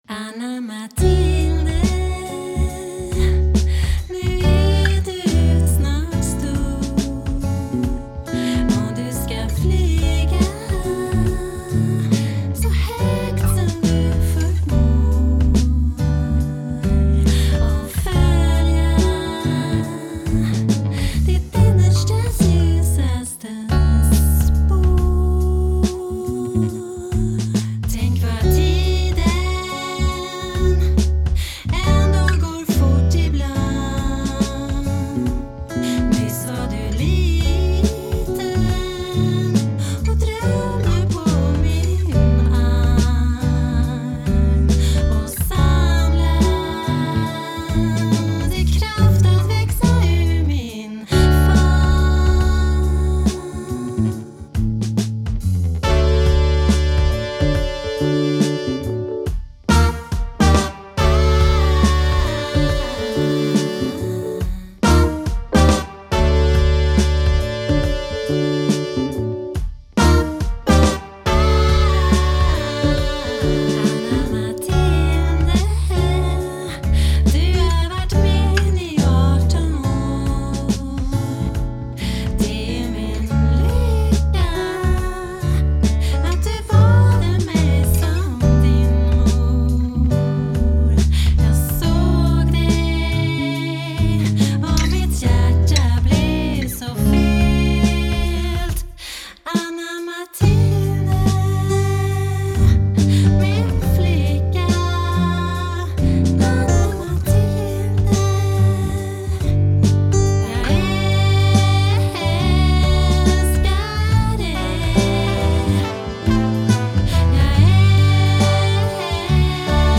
gitarr